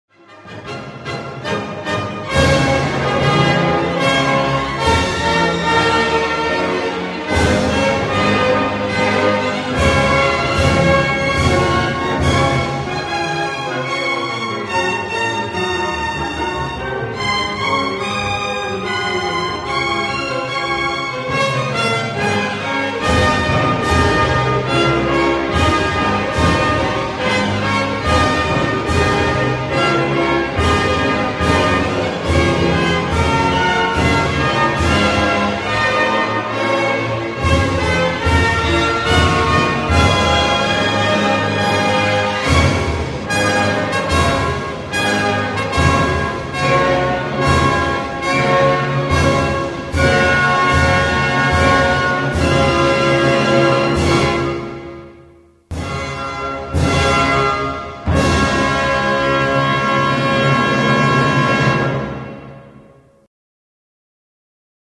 versione orchestrale
GaudeamusIgiturOrchestra.mp3